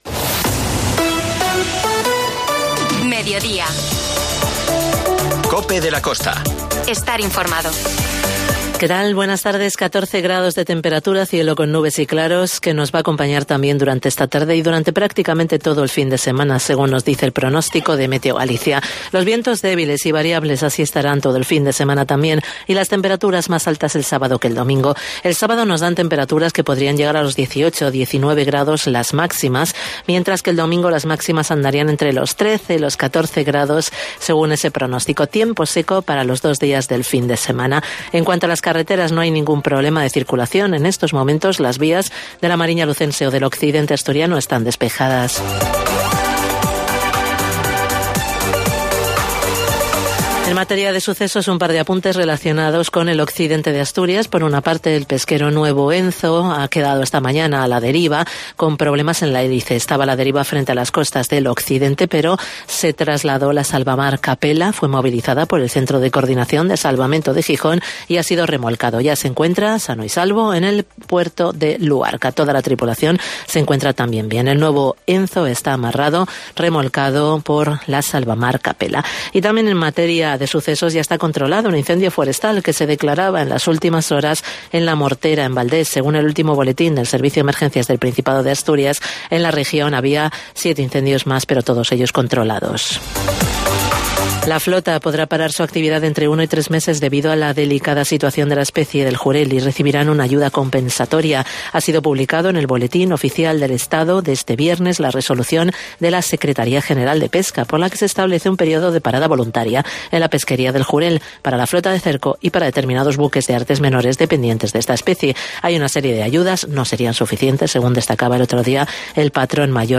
COPE de la Costa - Ribadeo - Foz INFORMATIVO